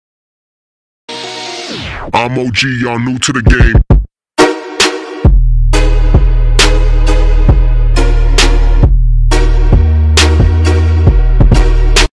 free-twitch-alert-followersubdonation-sound-effect-6_yLs7Pf9.mp3